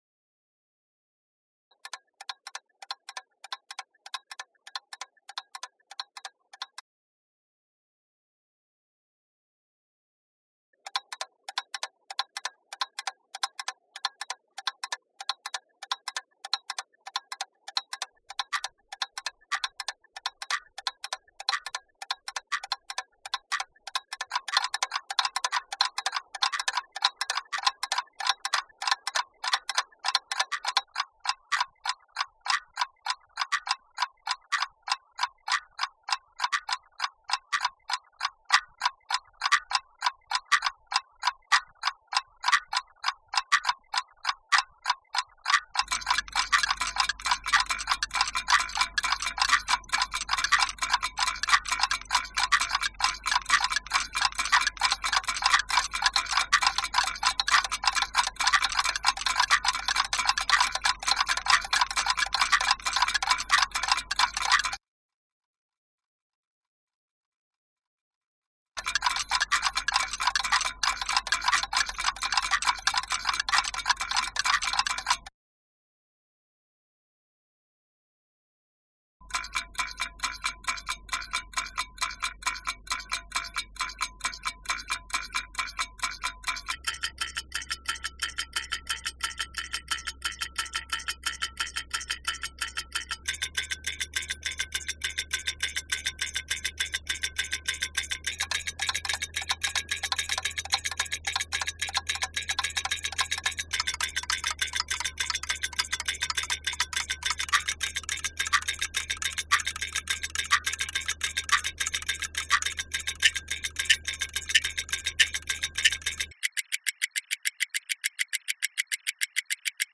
time… slips through my hands (iPad and Max/MSP)